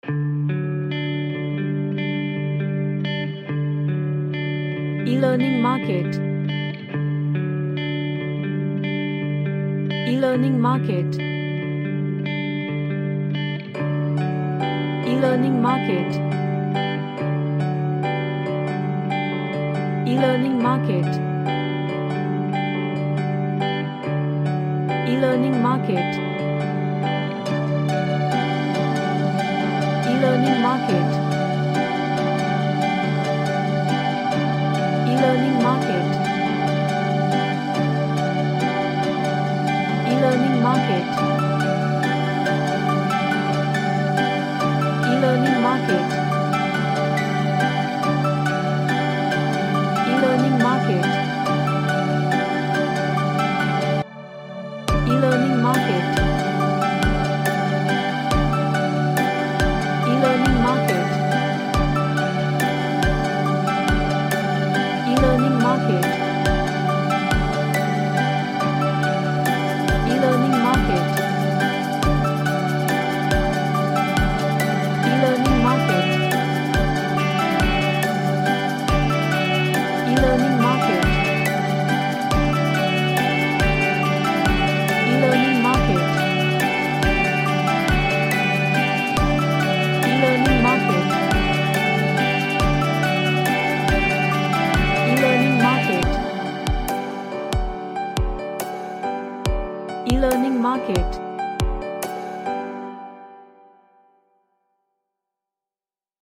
Magical sounding Pads sorrounded with Ambient Guitars
Magical / Mystical